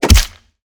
PUNCH_SQUELCH_HEAVY_01.wav